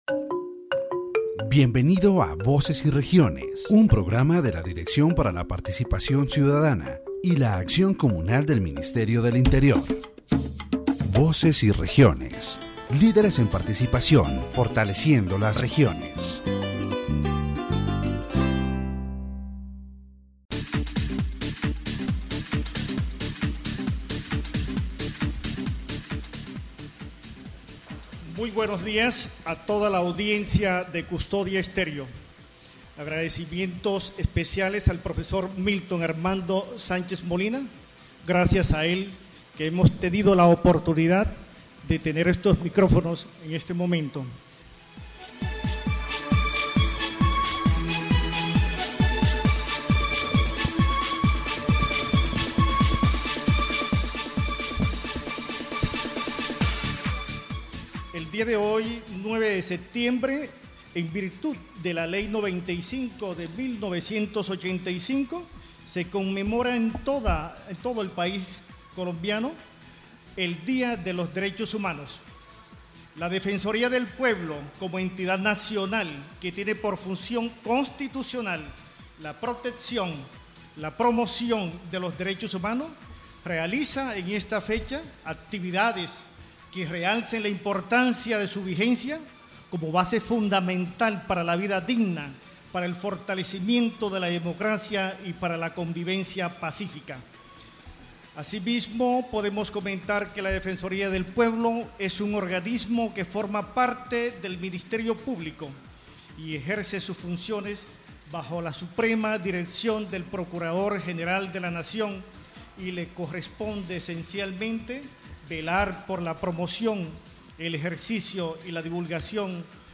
The broadcaster begins the program by commemorating the National Human Rights Day and highlighting Saint Peter Claver as an example of the fight for human dignity.